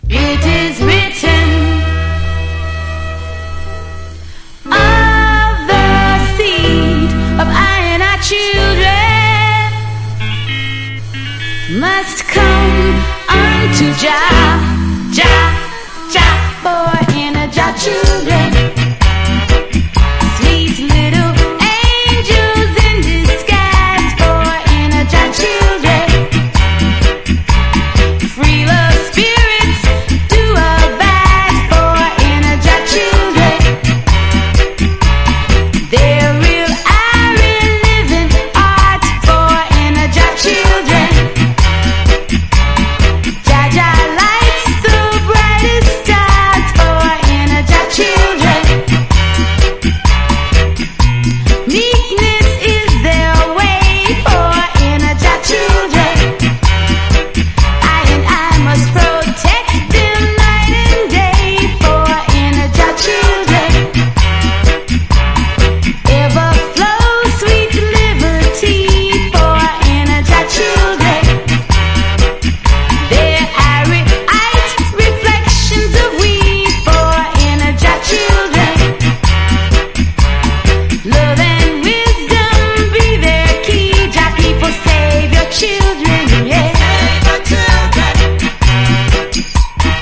• REGGAE-SKA
1. REGGAE >